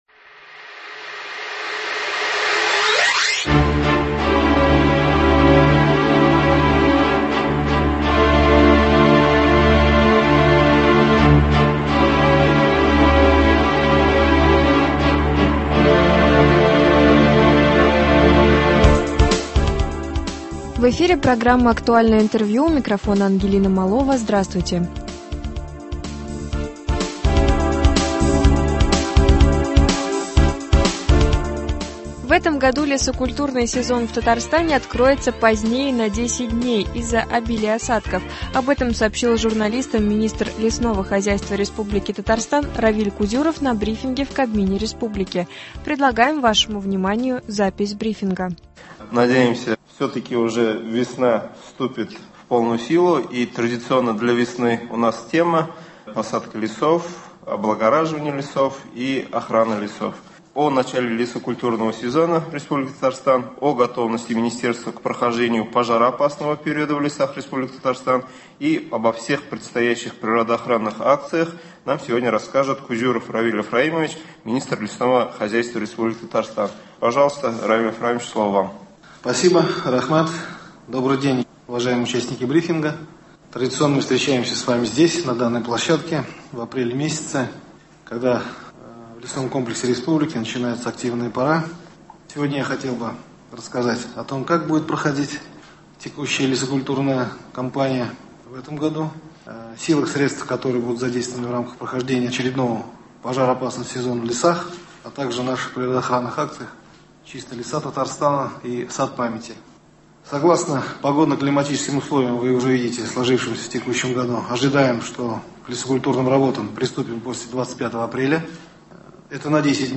Актуальное интервью (20.04.22)
В 2022 году Минлесхоз Татарстана планирует вырастить 34 млн штук сеянцев деревьев, что соответствует уровню прошлого года. Об этом сообщил министр лесного хозяйства Татарстана Равиль Кузюров на брифинге в Доме Правительства РТ.